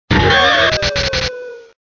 Fichier:Cri 0466 DP.ogg